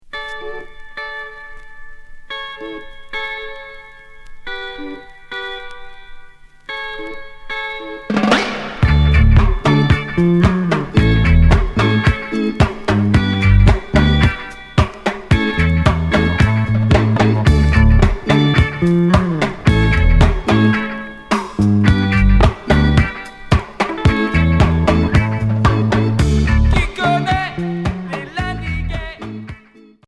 Reggae rock Sixième 45t retour à l'accueil